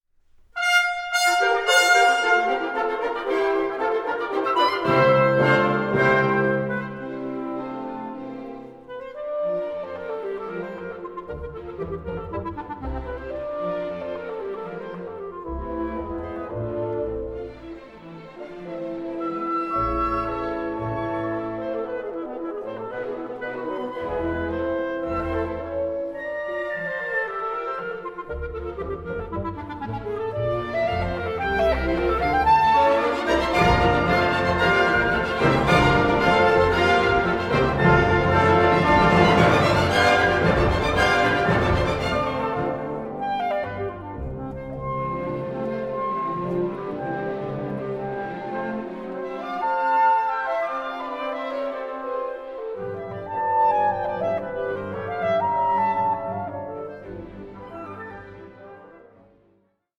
Vivace 4:09